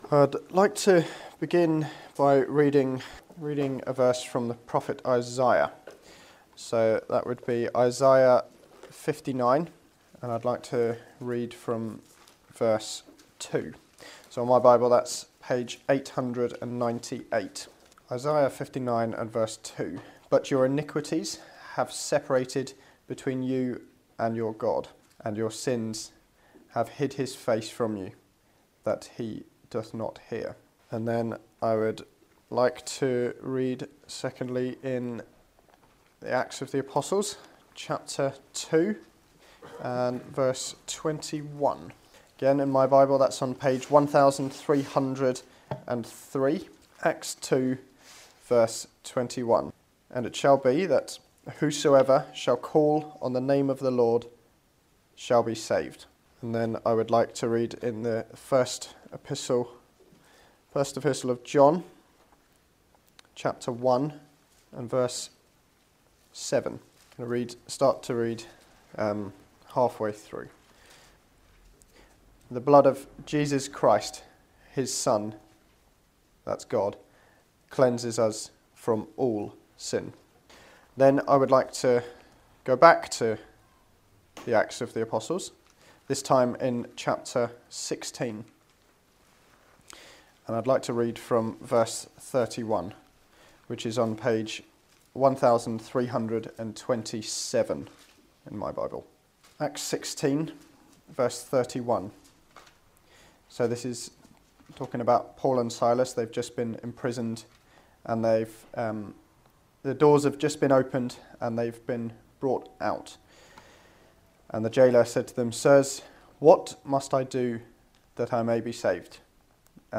Salvation is God's gift to you - a gift of freedom from sin, made possible by Jesus, who took our punishment on the cross. In this Gospel message, you will be presented with five key questions that reveal your need for salvation in the Lord Jesus.